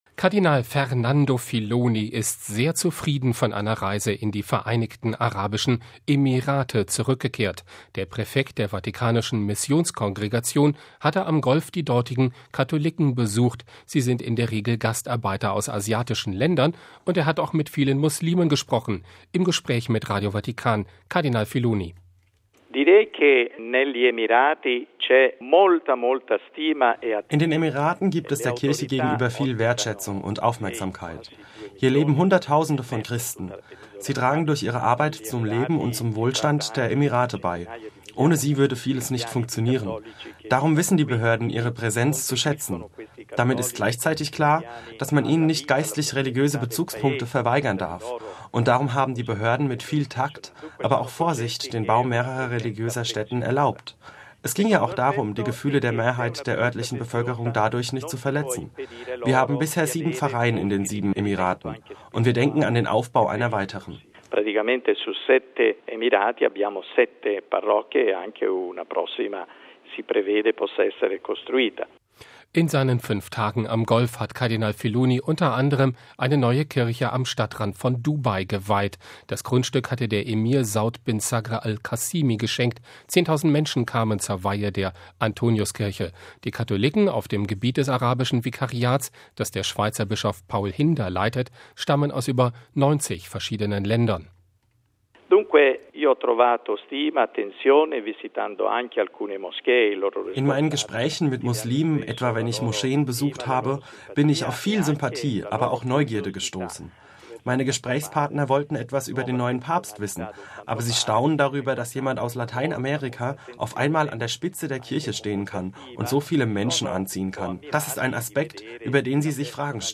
Der Präfekt der vatikanischen Missionskongregation hatte am Golf die dortigen Katholiken besucht, die in der Regel Gastarbeiter aus asiatischen Ländern sind, und auch mit vielen Muslimen gesprochen. Im Gespräch mit Radio Vatikan sagte Filoni: